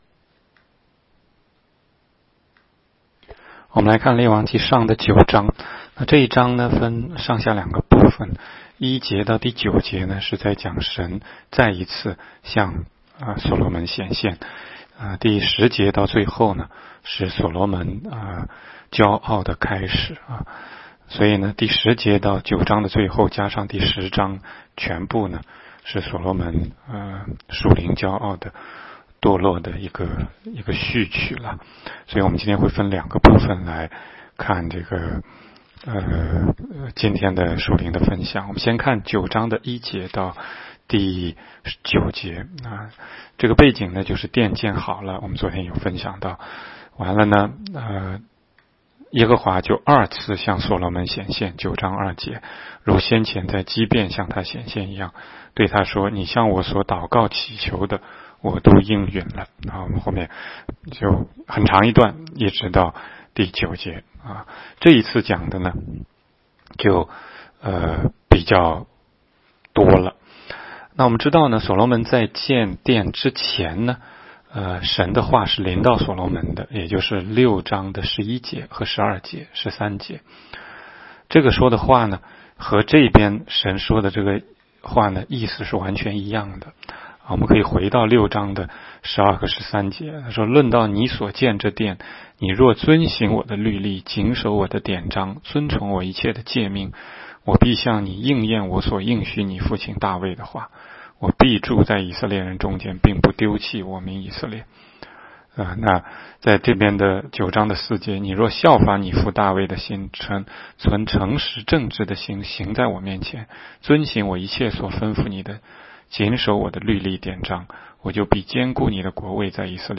16街讲道录音 - 每日读经-《列王纪上》9章